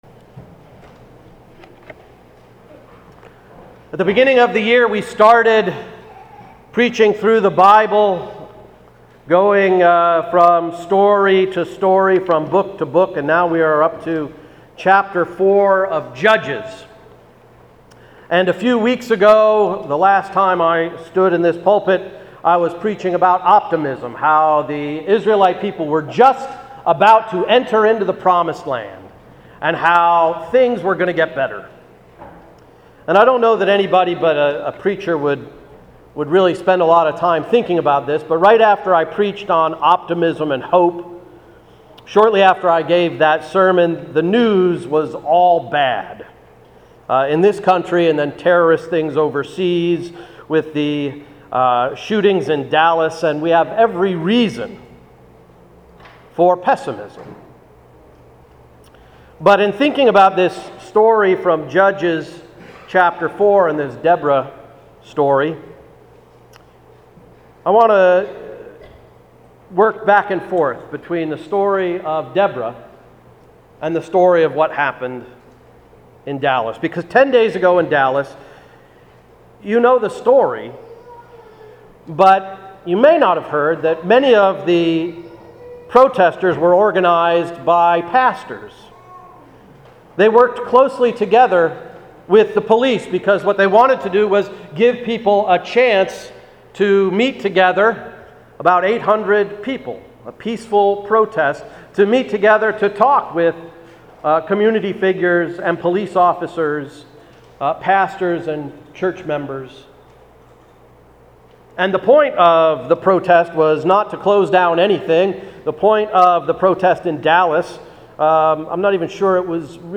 July 17, 2016 Sermon–“Seek and Find”